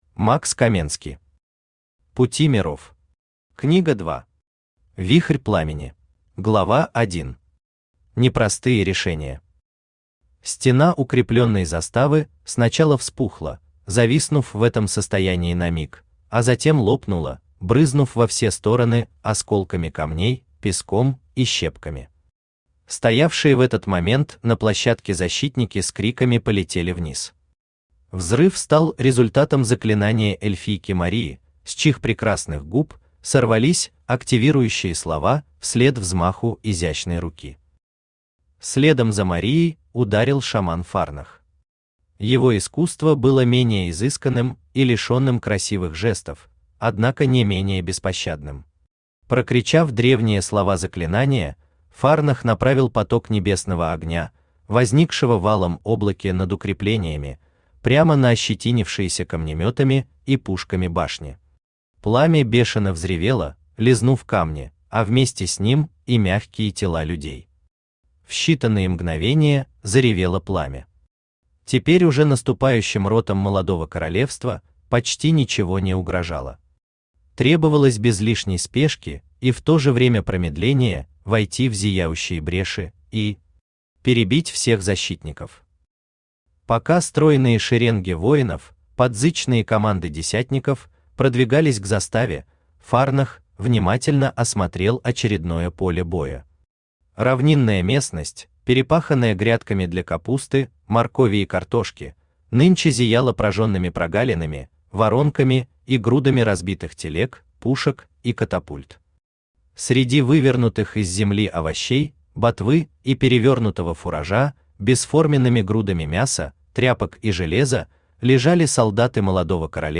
Аудиокнига Пути миров. Книга 2. Вихрь пламени | Библиотека аудиокниг
Вихрь пламени Автор Макс Каменски Читает аудиокнигу Авточтец ЛитРес.